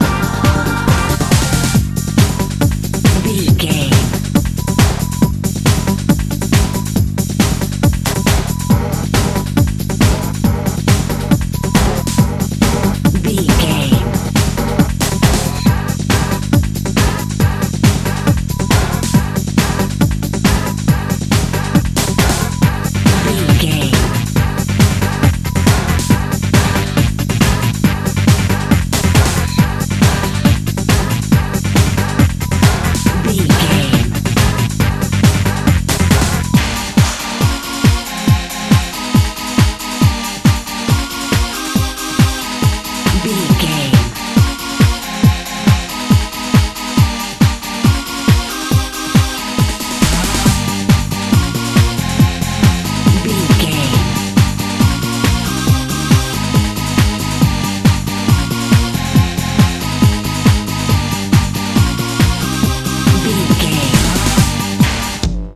Uplifting
Aeolian/Minor
Fast
drum machine
synthesiser
electric piano
conga